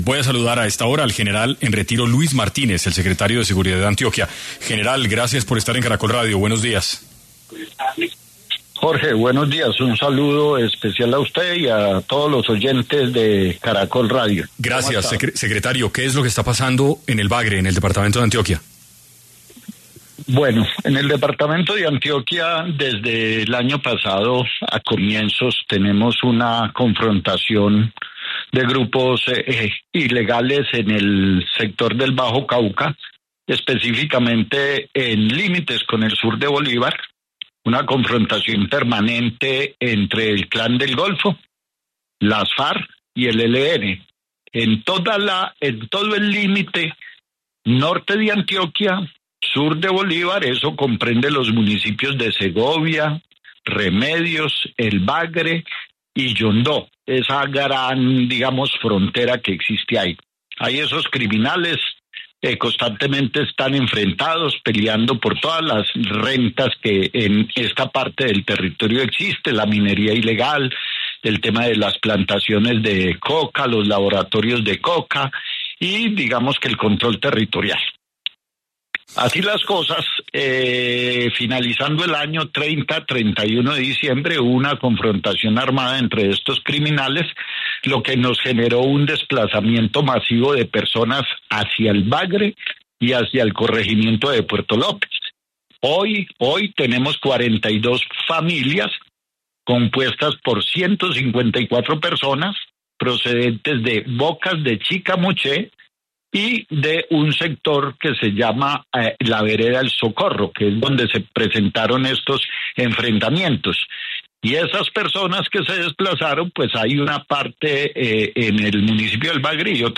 En Caracol Radio estuvo Luis Martínez, secretario de Seguridad de Antioquia